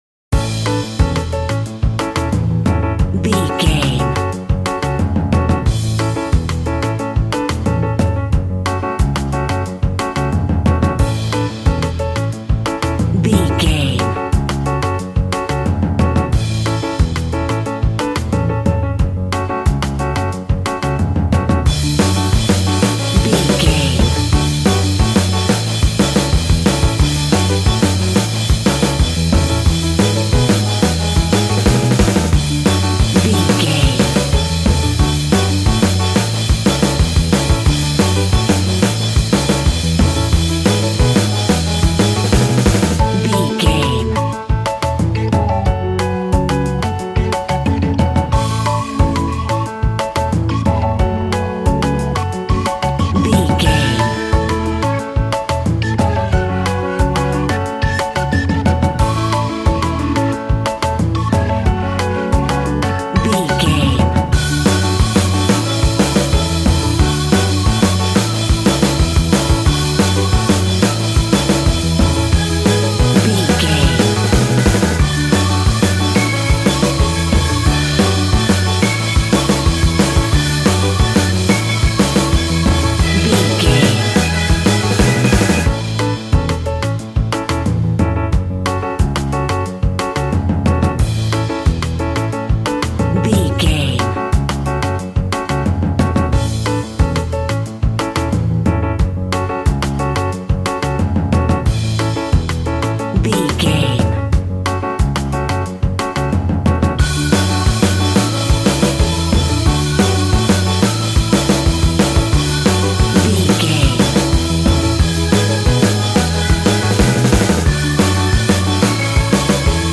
Ionian/Major
cheerful/happy
double bass
drums
piano